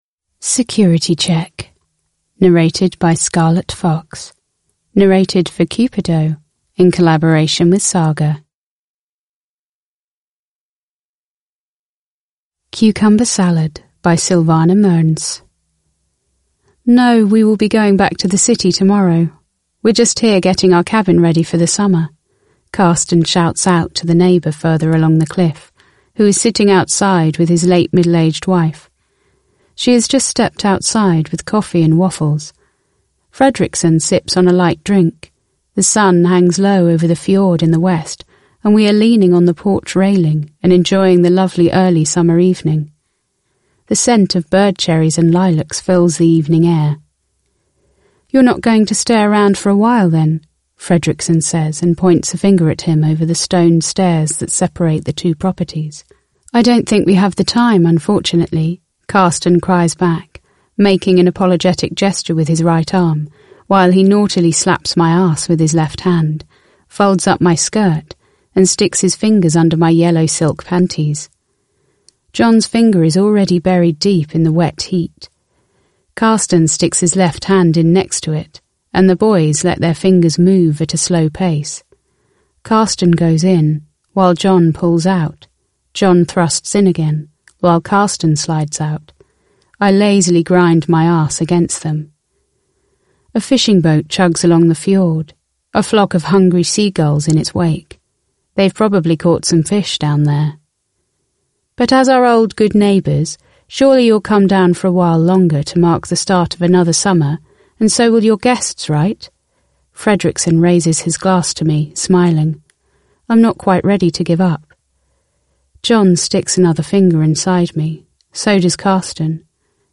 Security check (ljudbok) av Cupido